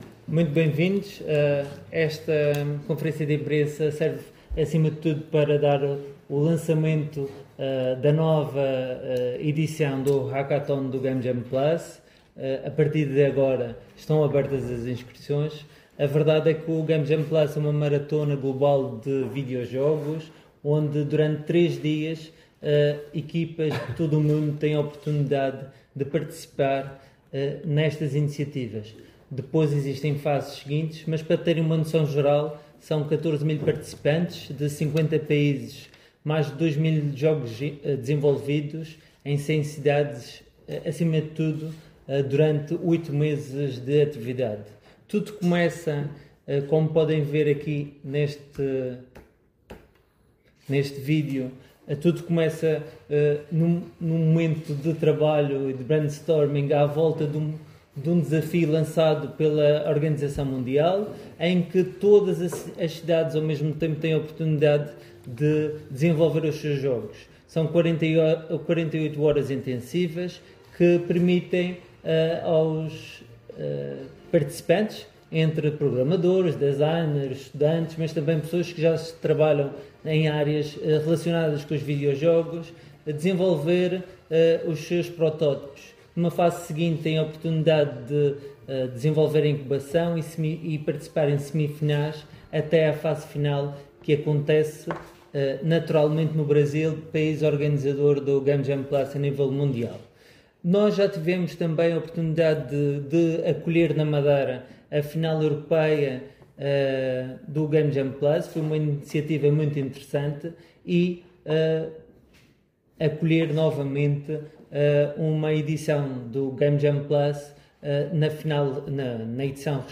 O Secretário Regional da Economia, José Manuel Rodrigues, presidiu, hoje, à cerimónia de apresentação pública da 3.ª edição do GameJam+ Madeira, que irá decorrer de 17 a 19 de outubro de 2025, na Região Autónoma da Madeira.